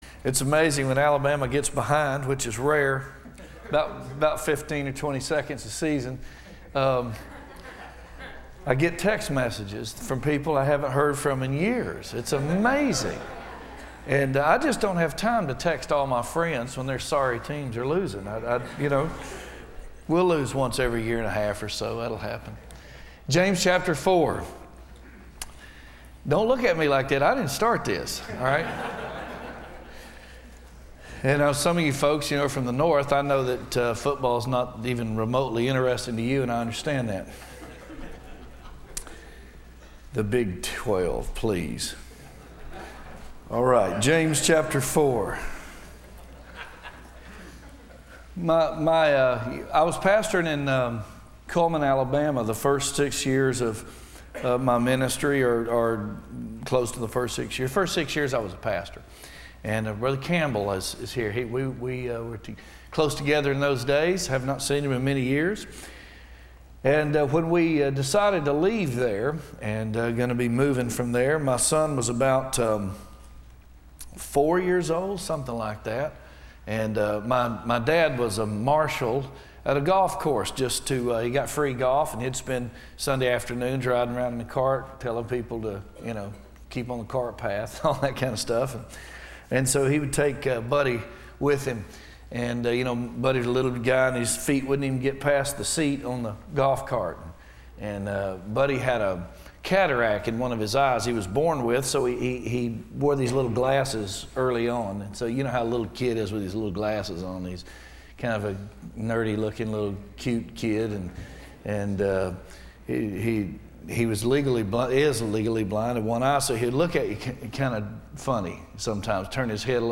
Service Type: Bible Conference